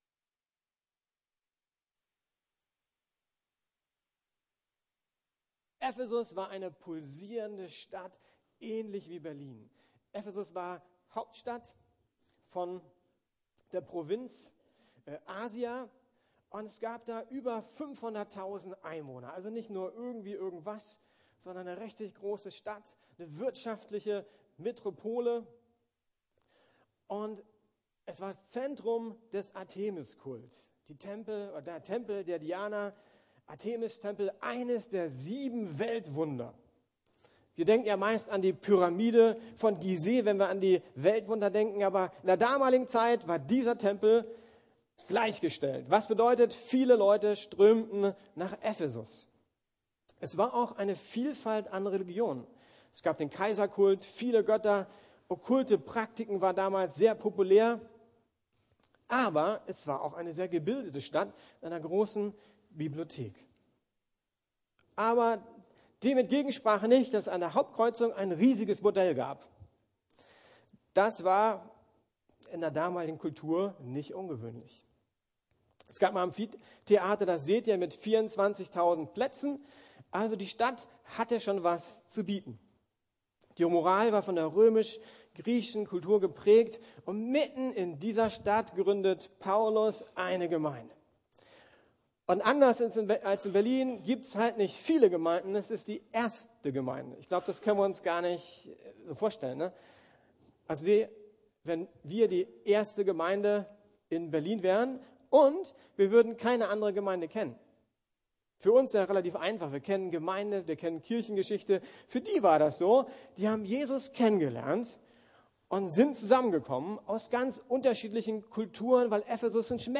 Gemeinsam wachsen ~ Predigten der LUKAS GEMEINDE Podcast